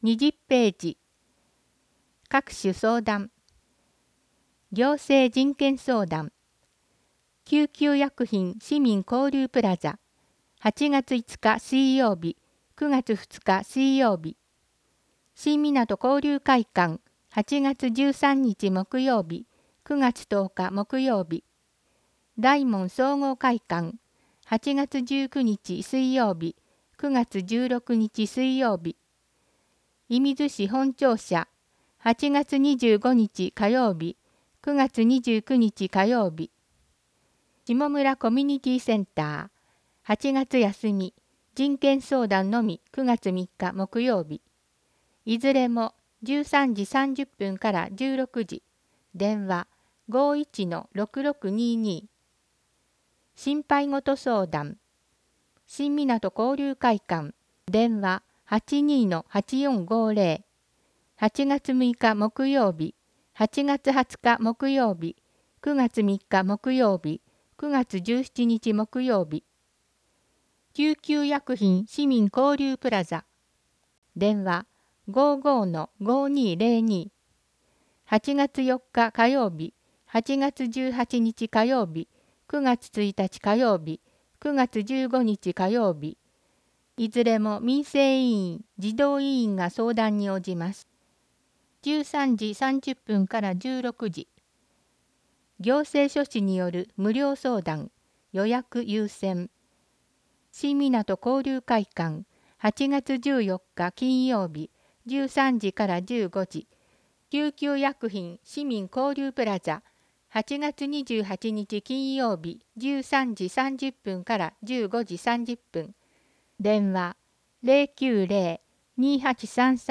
広報いみず 音訳版（令和２年８月号）｜射水市